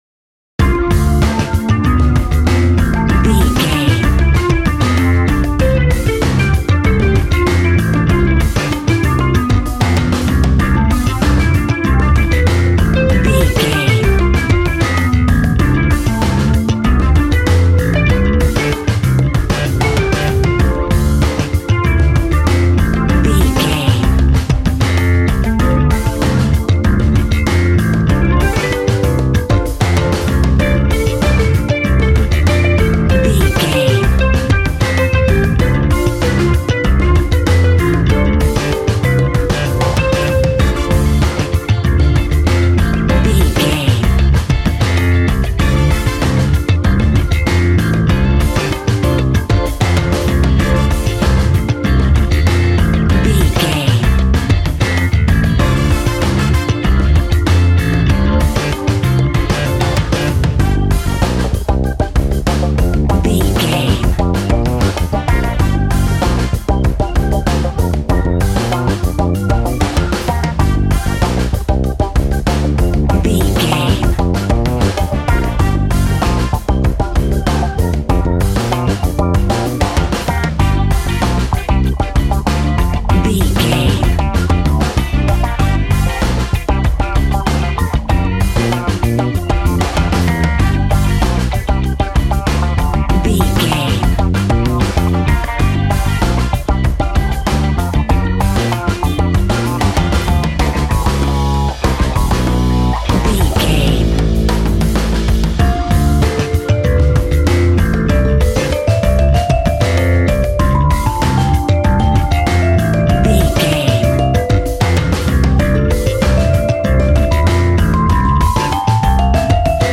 Aeolian/Minor
relaxed
smooth
synthesiser
drums
80s